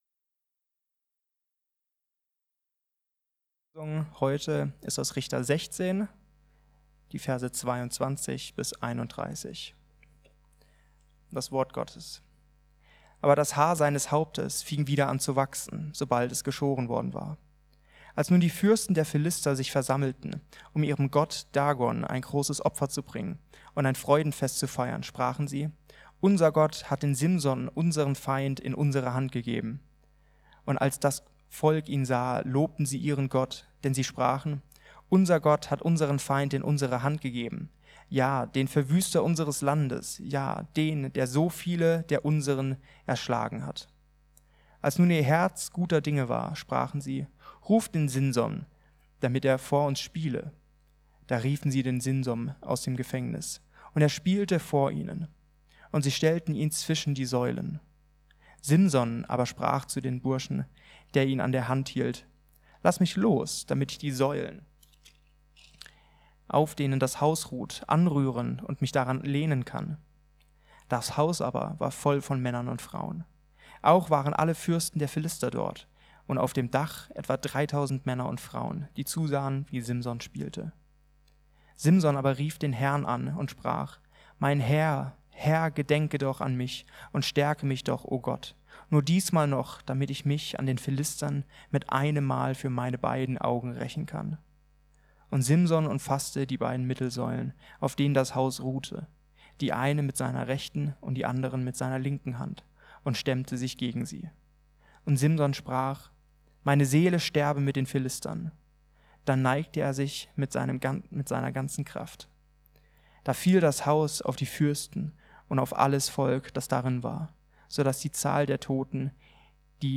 Simsons Sieg im Tod ~ Mittwochsgottesdienst Podcast